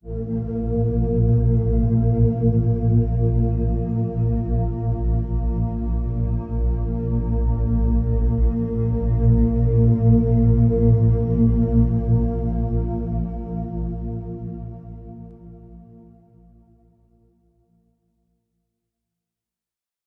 描述：使用颗粒合成（我自己的实现）对尺八样本进行了大量的处理，这个声音是为即兴演奏/表演中使用的一个D而制作的
Tag: 粒状 尺八 合成